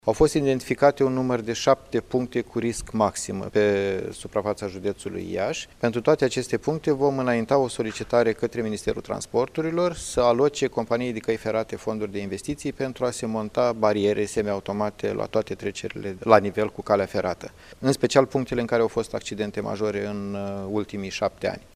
Prefectul Dan Cârlan, a precizat că în comisia de circulație, de astăzi, au fost identificate 7 treceri la nivel cu calea ferată care sunt periculoase pentru conducătorii auto.